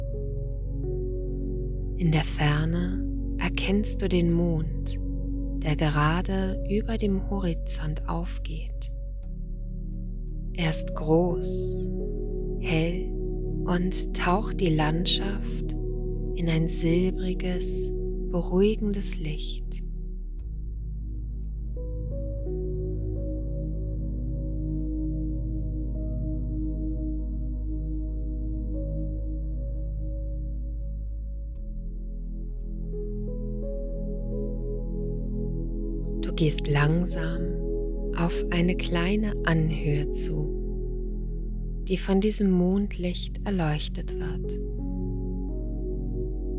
Hier kannst du eine kurze Hörprobe aus der Fantasiereise herunterladen.